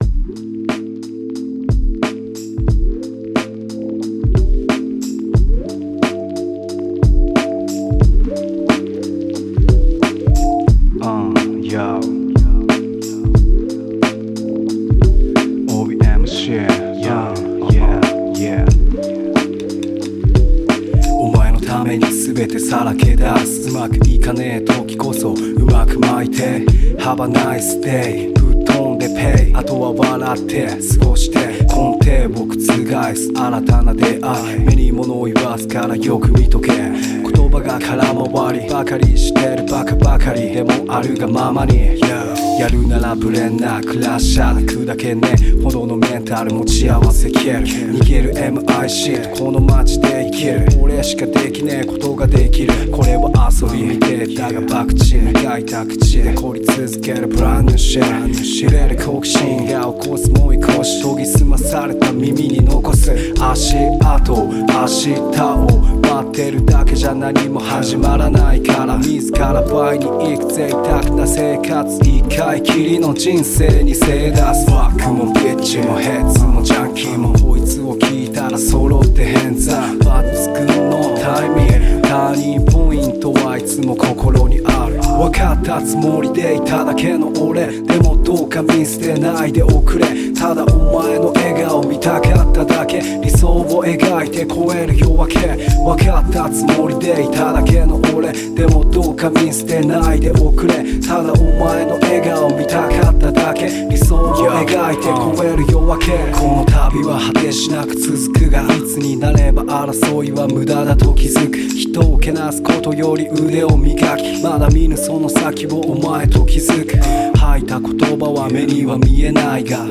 poet spoken words and reggae gospel artist.